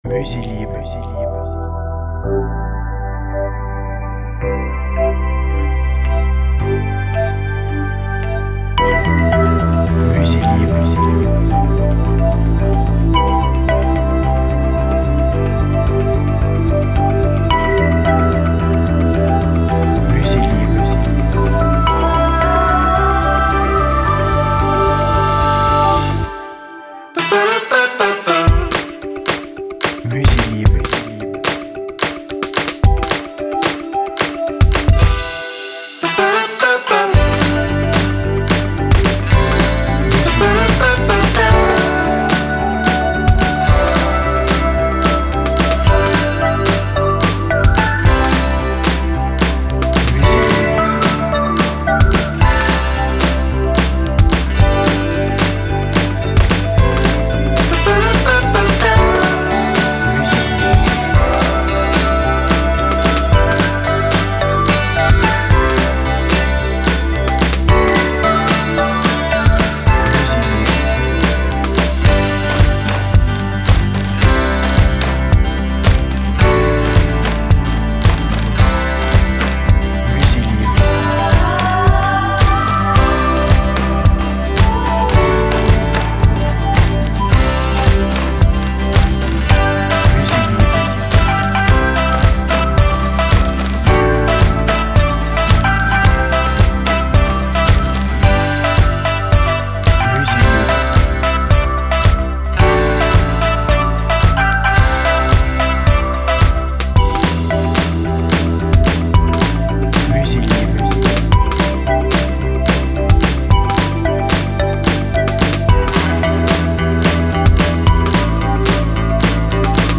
BPM Rapide